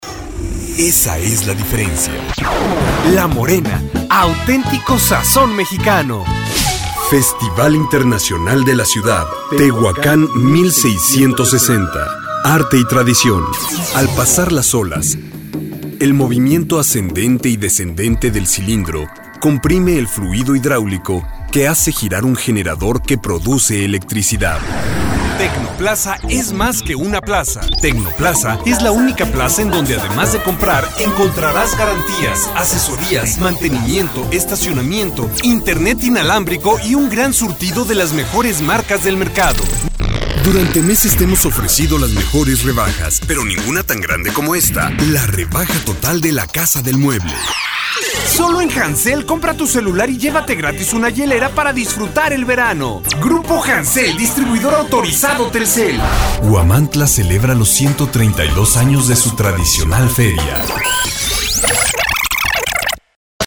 Professionelle Sprecher und Sprecherinnen
Spanisch-Mexikanisch
Männlich